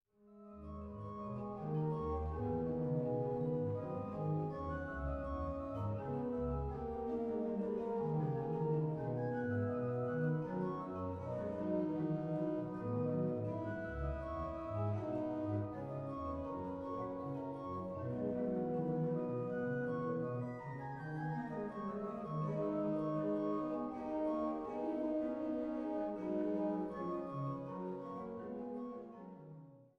Bad Lausick